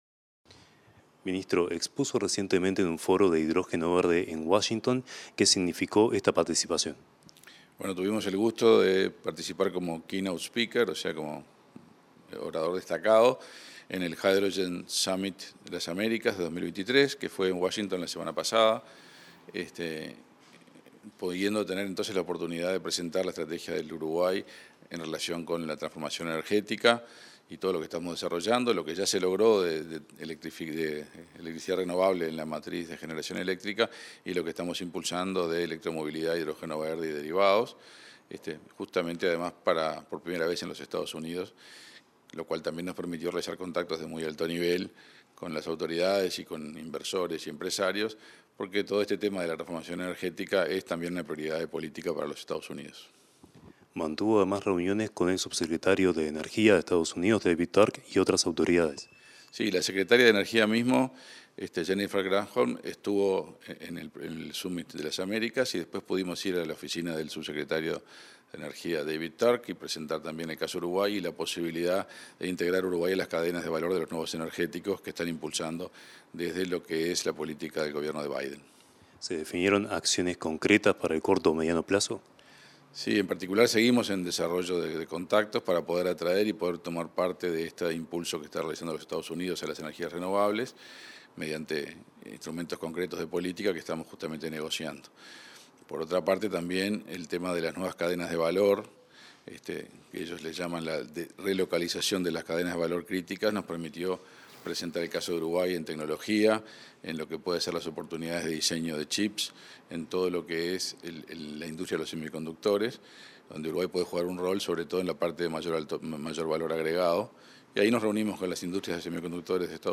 Entrevista al ministro de Industria, Omar Paganini